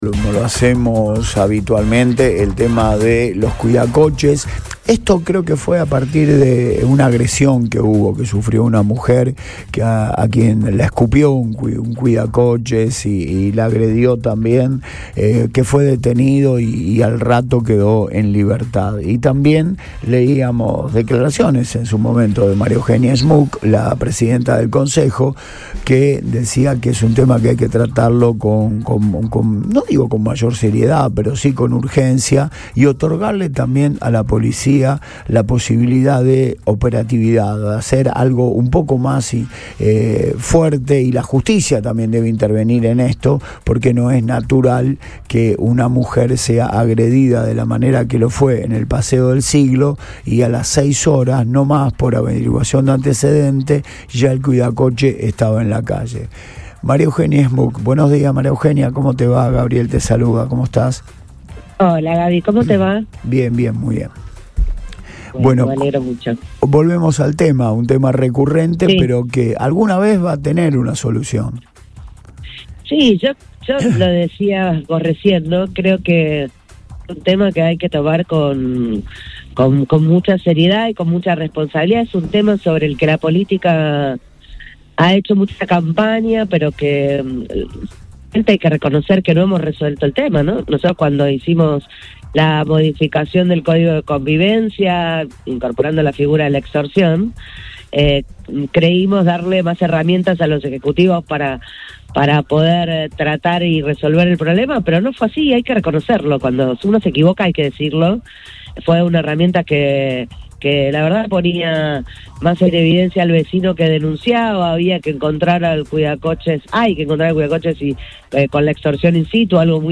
La presidenta del Concejo Municipal de Rosario, María Eugenia Schmuck, volvió a poner en agenda la problemática de los cuidacoches en la ciudad y reclamó medidas más firmes y coordinadas entre los distintos poderes del Estado. Lo hizo en diálogo con el programa Antes de Todo, que se emite por Radio Boing.